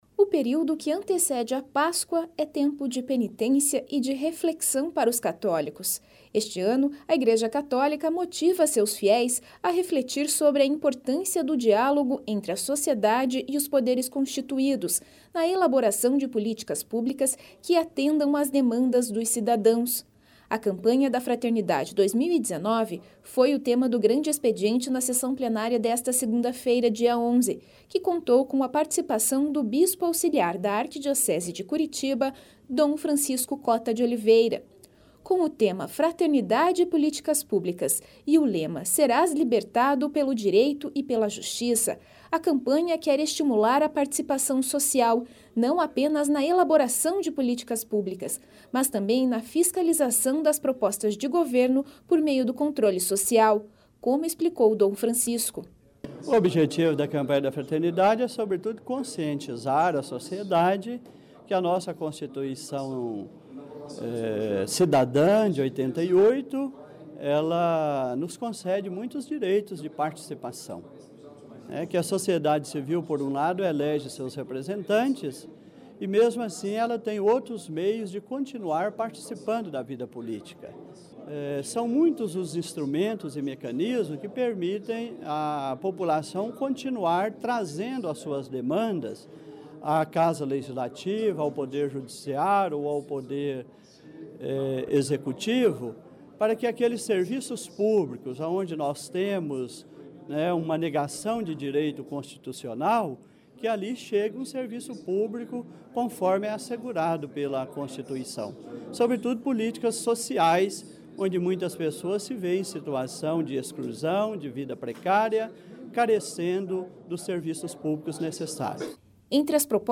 A Campanha da Fraternidade 2019 foi o tema do Grande Expediente na sessão plenária desta segunda-feira, dia 11, que contou com a participação do bispo auxiliar da Arquidiocese de Curitiba, Dom Francisco Cota de Oliveira.